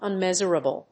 発音
• IPA(key): /ʌnˈmɛʒəɹəbəl/, /ʌnˈmɛʒɹəbəl/